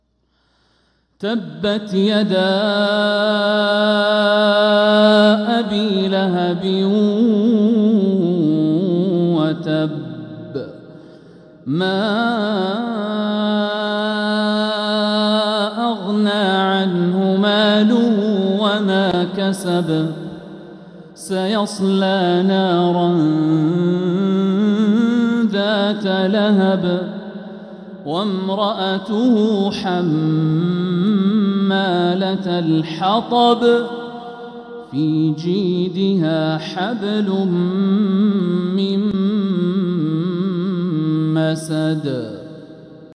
سورة المسد | فروض ربيع الأخر 1446هـ
من الحرم المكي